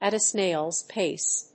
at a snáil's páce